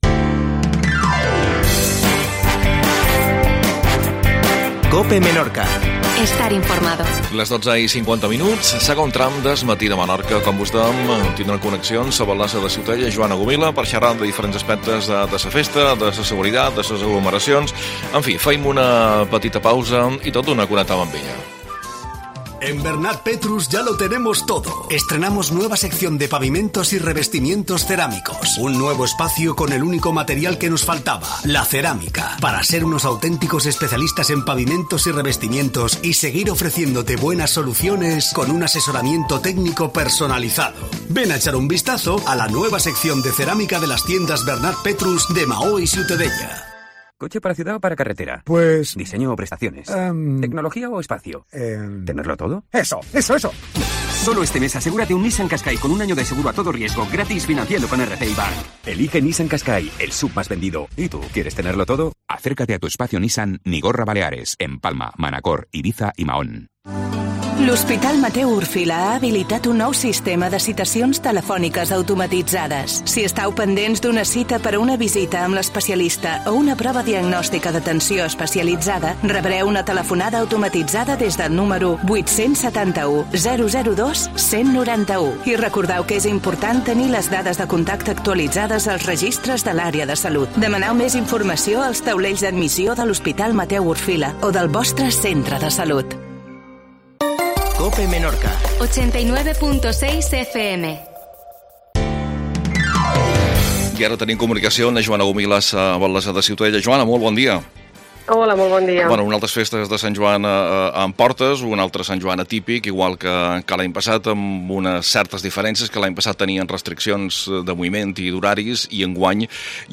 AUDIO: Joana Gomila, Alcaldesa de Ciutadella